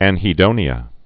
(ănhē-dōnē-ə)